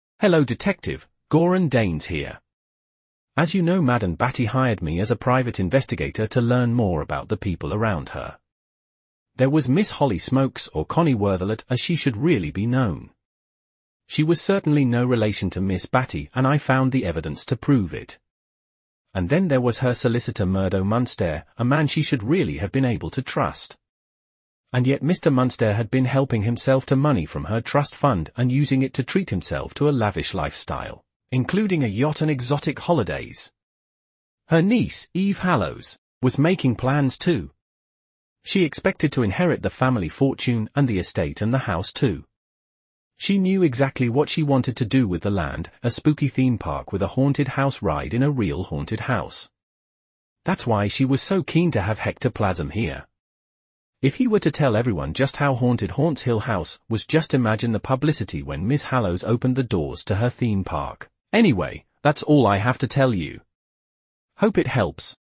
Voicemail